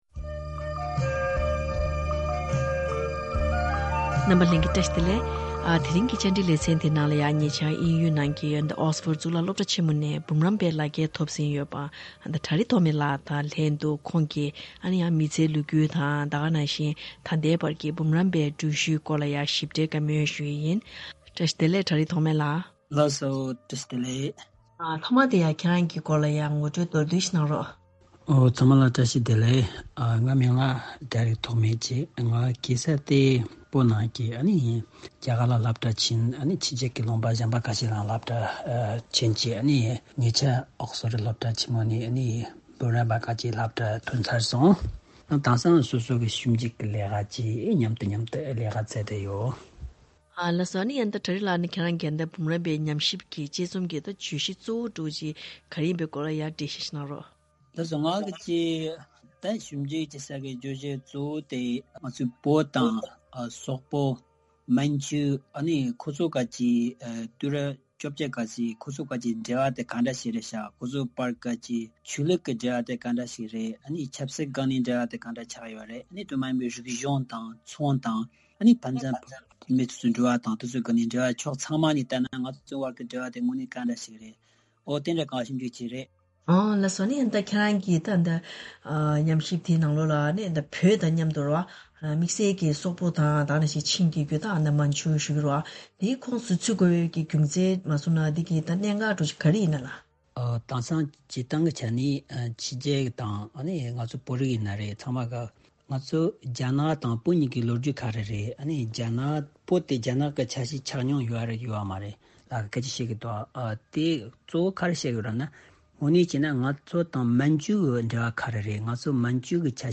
བཀའ་དྲི་ཞུས་པ་ཞིག་གསན་གནང་གི་རེད།